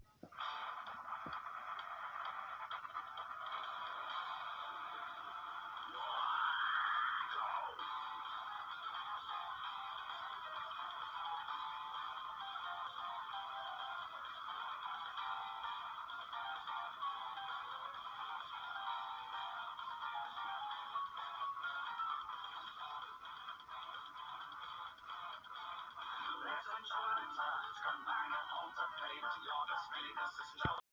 rock version